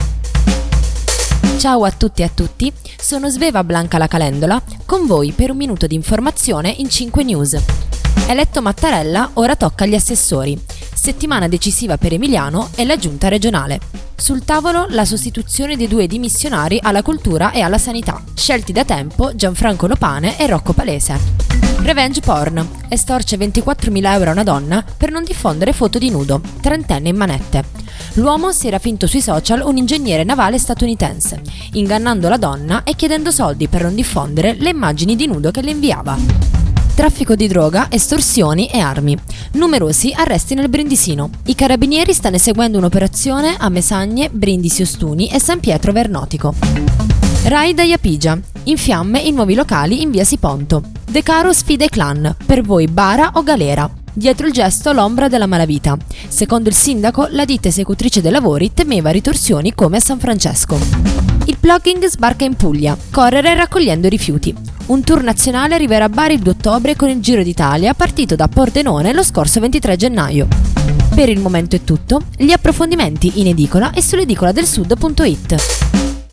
Giornale radio alle ore 13.